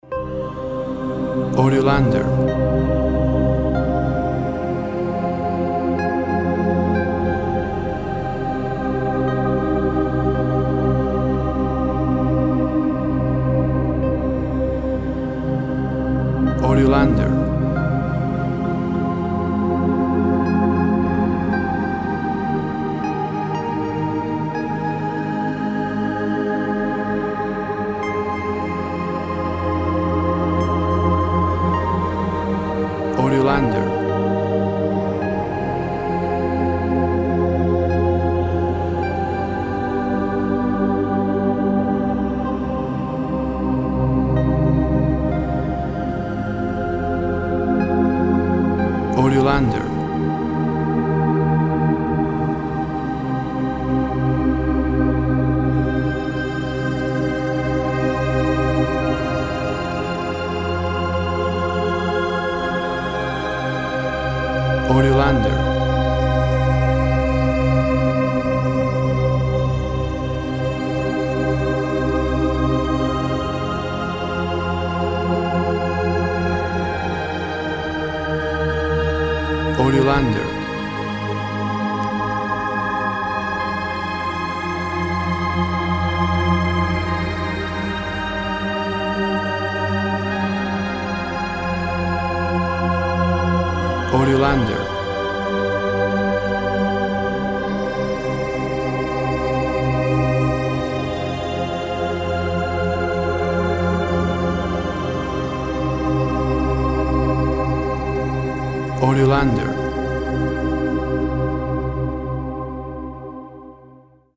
Tempo (BPM) 60